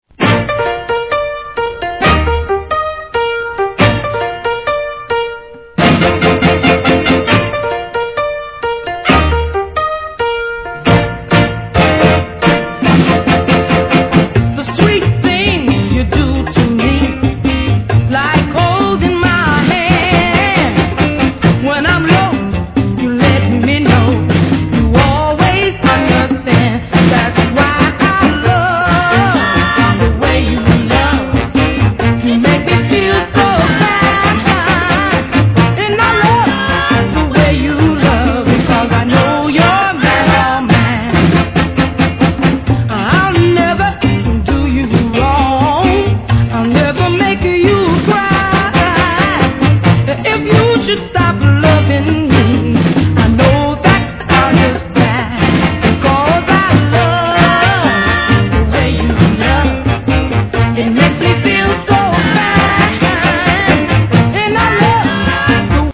¥ 1,100 税込 関連カテゴリ SOUL/FUNK/etc...
当時10代とは思えない歌唱力で聴かせます!